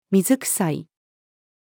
水臭い-female.mp3